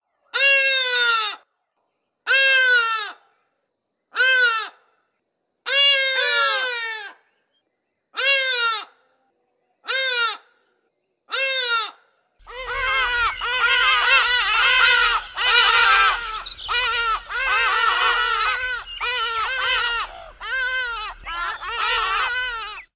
Hadada sounds – Kenga Publishing
hadada-ibis-call.mp3